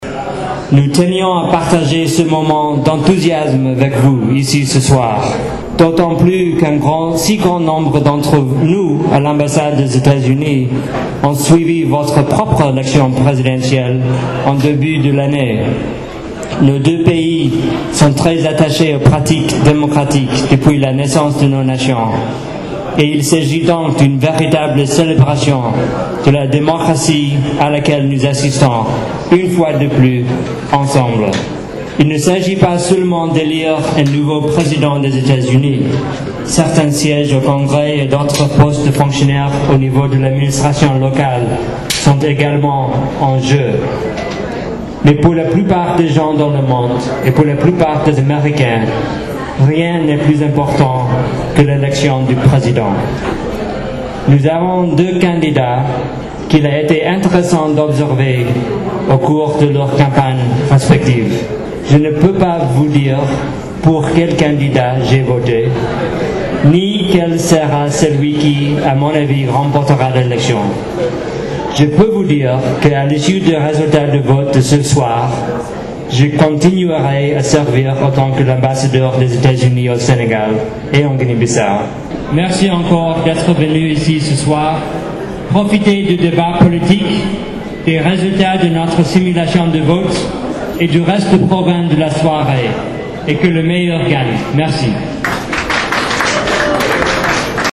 Discours de l'ambassadeurs des USA à Dkr.mp3 (1.49 Mo)
» Son excellence Monsieur Lewis Lukens, ambassadeur des Etats-Unis au Sénégal, au cours de la soirée électorale des USA organisée à Dakar, a soutenu que quel que soit le résultat de la présidentielle américaine qui met en prise le président sortant, Barack Obama et le candidat républicain, Mitt Romney, il va continuer à accomplir sa mission en tant qu’Ambassadeur des Etats-Unis au Sénégal.